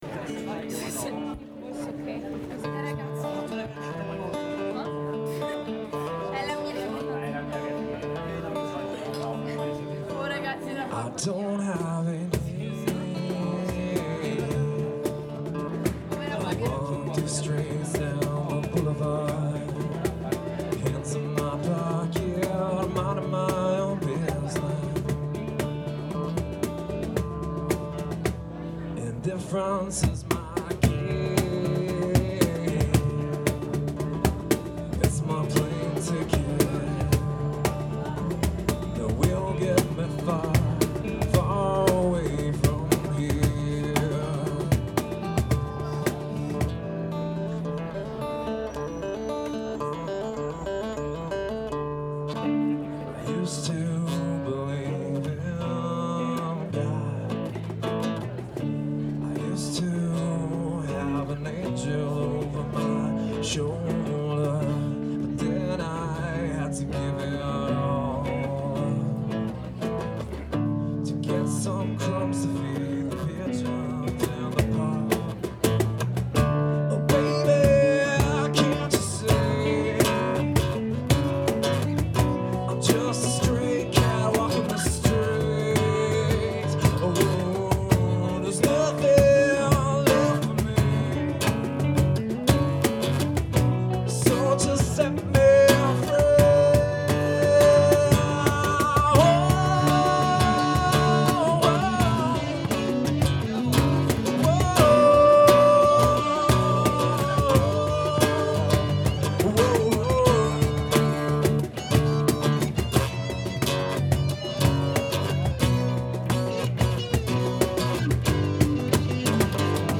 singer and guitarist
drummer
bassist and double bassist